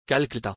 splat_calcutta_pronounciation2.mp3